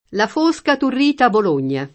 fosco [f1Sko] agg.; pl. m. foschi — es. con acc. scr.: la fósca turrita Bologna [